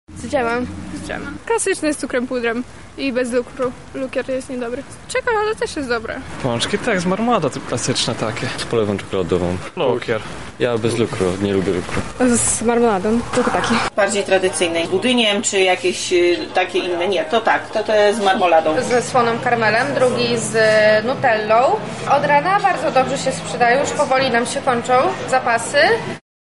O ten popularny przysmak zapytaliśmy mieszkańców Lublina.
SONDA